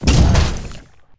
battlesuit
move2.wav